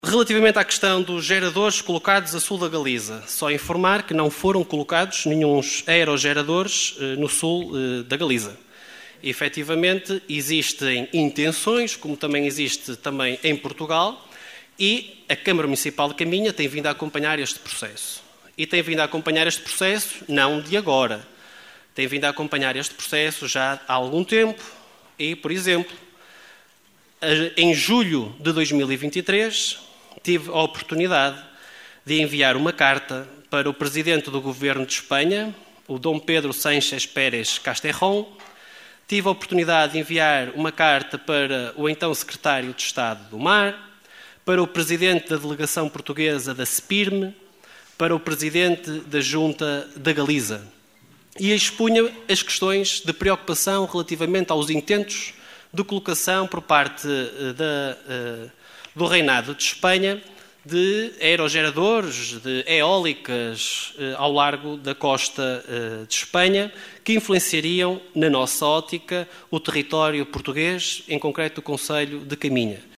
O parque eólico que está projetado para o sul da Galiza, a dívida à Luságua, a questão do CET, o Ferry Boat e o estado “vergonhoso”  em que se encontram as ruas e estradas municipais foram algumas das questões deixadas pelo deputado da coligação O Concelho em Primeiro (OCP) Jorge Nande ao Presidente da Câmara Rui Lages no período antes da ordem do dia da última assembleia municipal de Caminha.
Rui Lages, Presidente da Câmara Municipal de Caminha